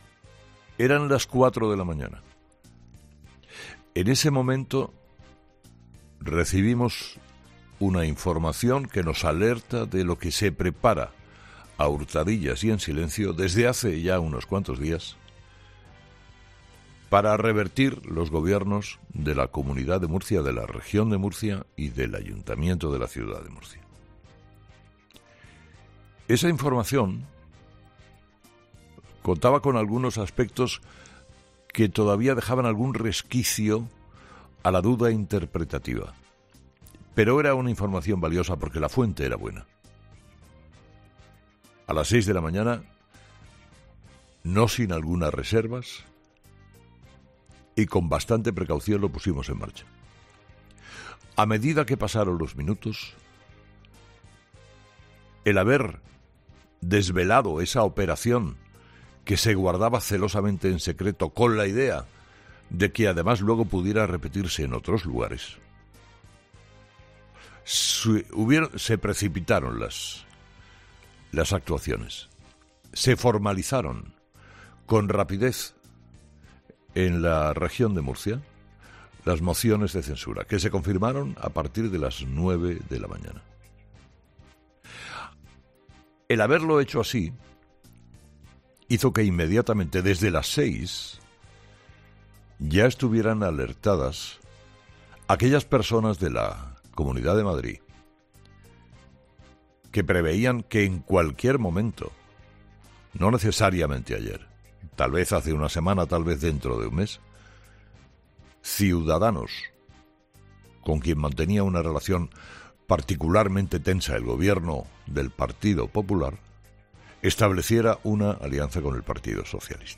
El presentador y director de 'Herrera en COPE', Carlos Herrera, ha analizado en el primer monólogo de las seis de la mañana la batalla política que se está viviendo en las últimas horas en la política española tras las moción que han presentado este miércoles en la región de Murcia PSOE y Ciudadanos.